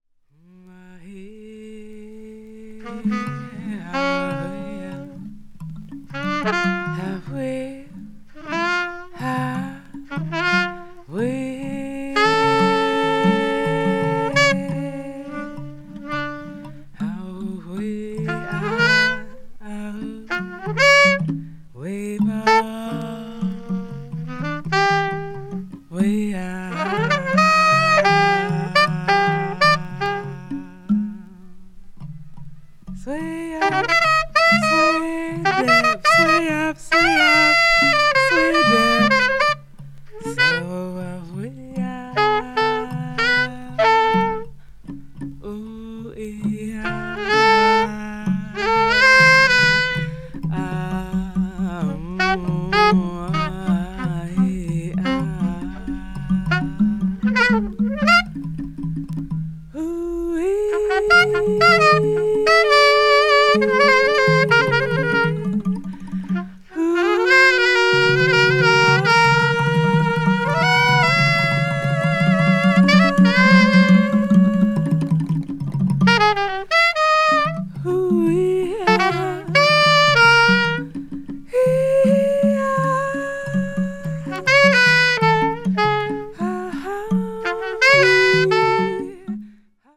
多様な民族打楽器を駆使したアフロ・エスニックな趣向によるフリー・インプロヴィゼーションを展開した素晴らしい内容です。